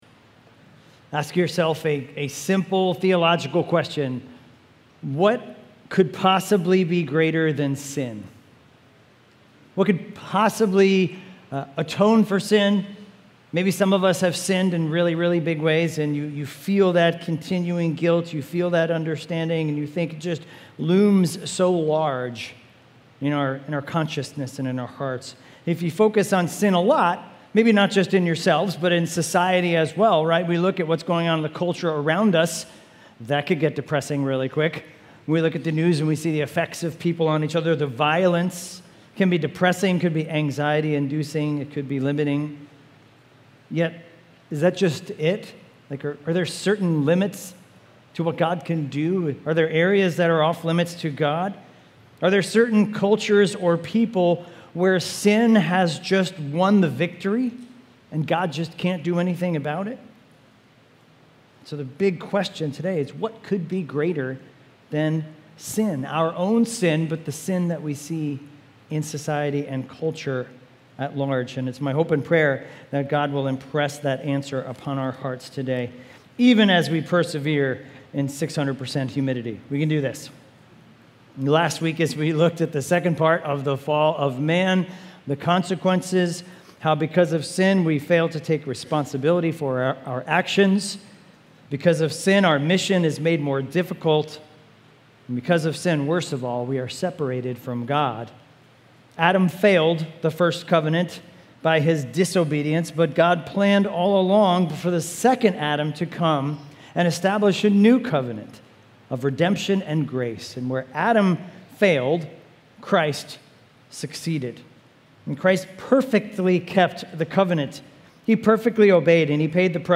Weekly Sunday AM sermon series in Genesis.